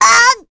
Rephy-Vox_Damage_kr_01.wav